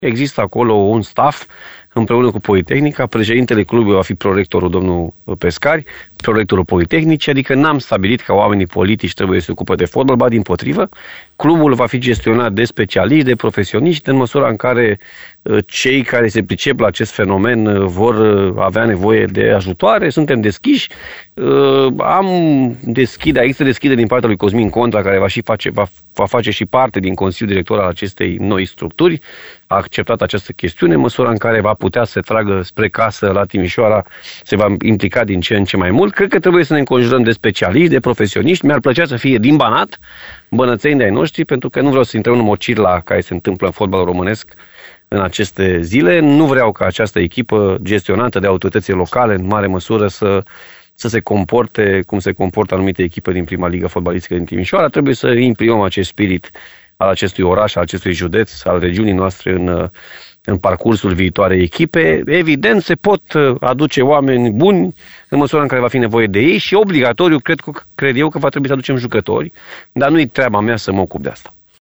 Președintele Consiliului Județean Timiș a fost invitat la ediția de sâmbătă a emisiunii Arena Radio.